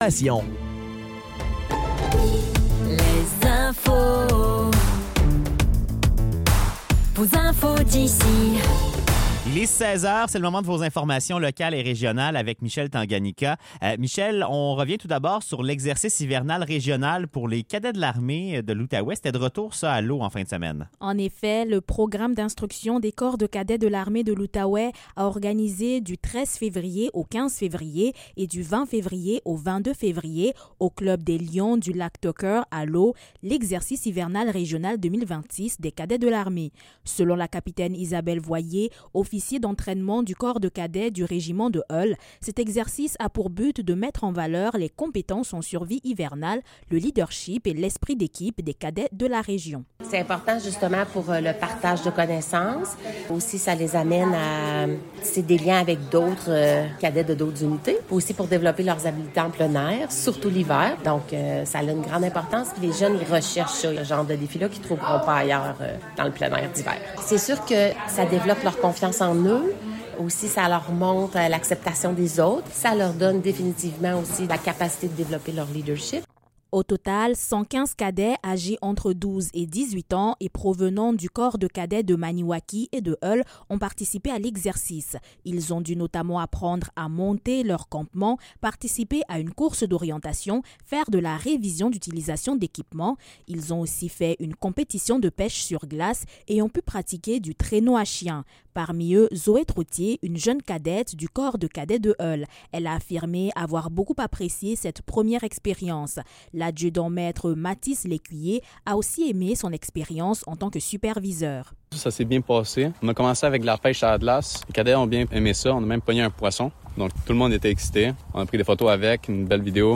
Nouvelles locales - 23 février 2026 - 16 h